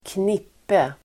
Uttal: [²kn'ip:e]